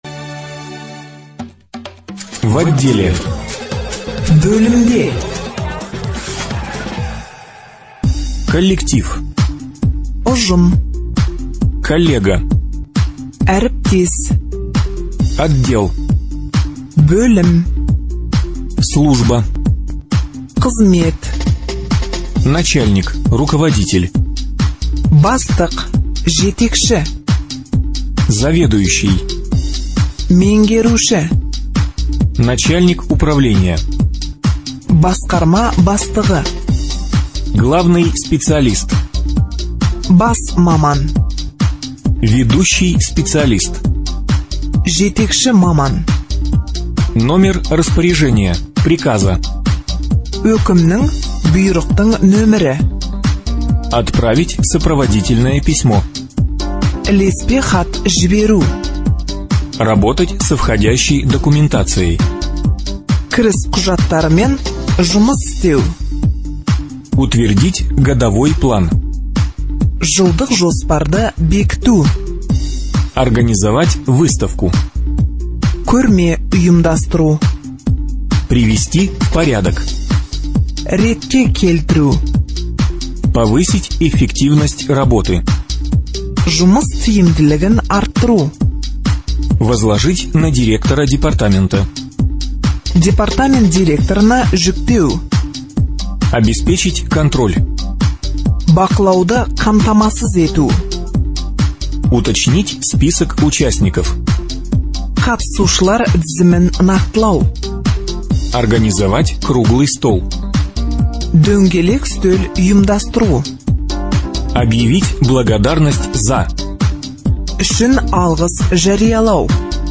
Говорим (аудио разговорники)